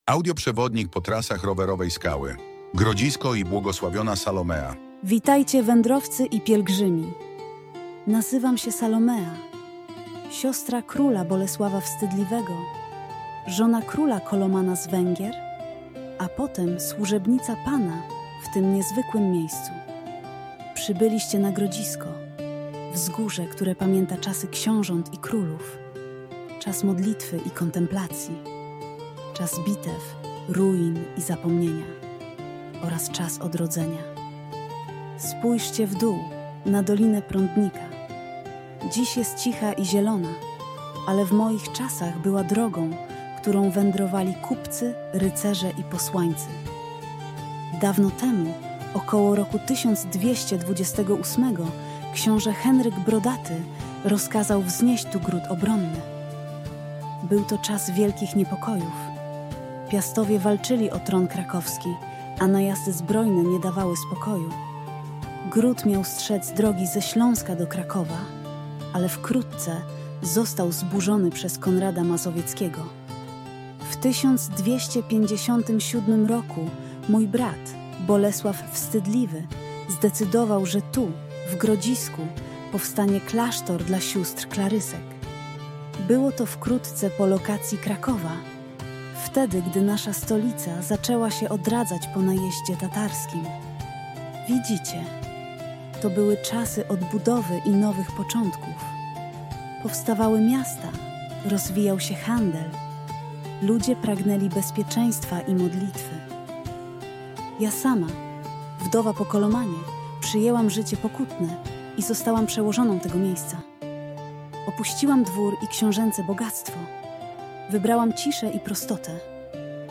audiobook_salomea_quieter_6db-mfnp4skg.mp3